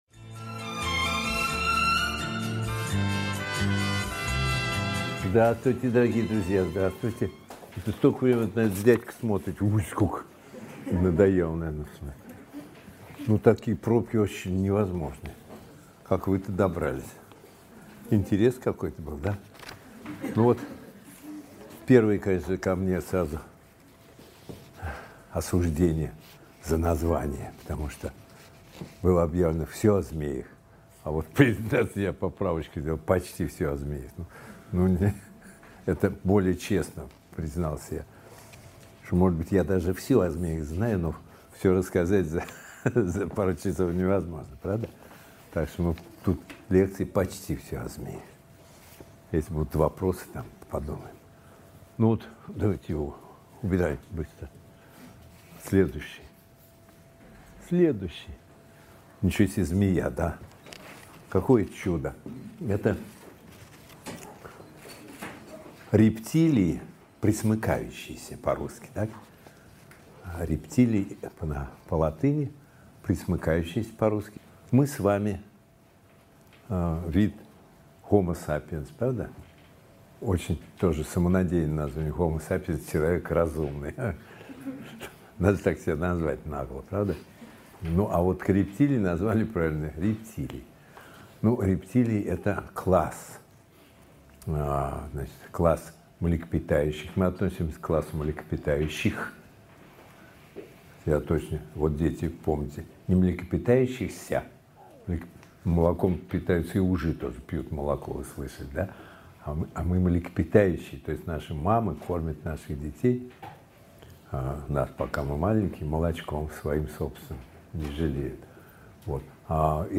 Аудиокнига Почти всё о змеях | Библиотека аудиокниг
Aудиокнига Почти всё о змеях Автор Николай Дроздов Читает аудиокнигу Николай Дроздов.